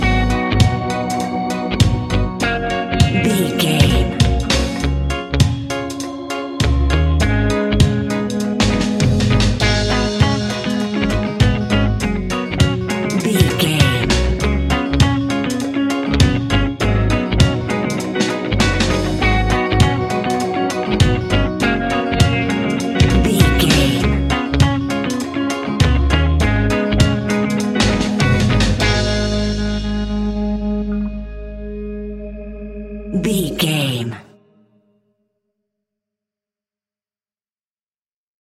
A nice bouncy and upbeat piece of Reggae music.
Aeolian/Minor
G#
Slow
reggae instrumentals
laid back
off beat
drums
skank guitar
hammond organ
percussion
horns